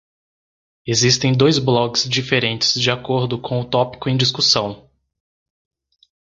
Pronunciado como (IPA)
/ˈtɔ.pi.ku/